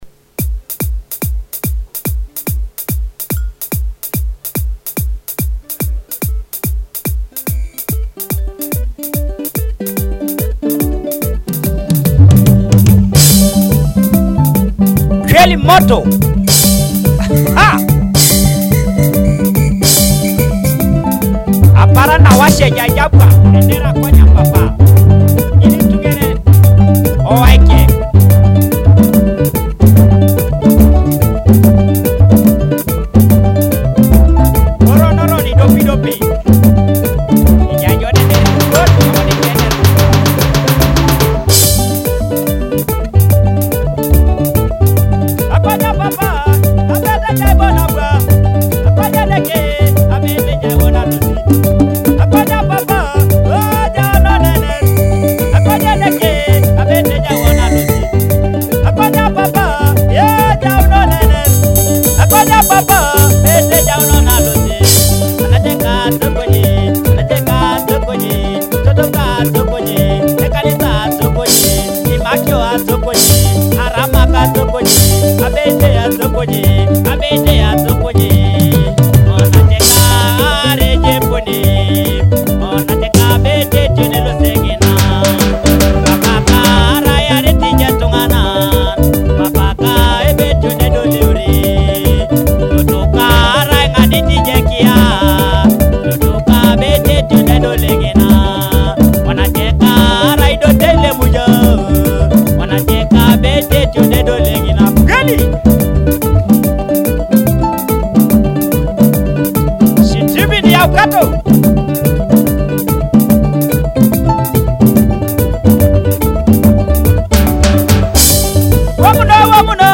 a heartfelt Ateso gospel song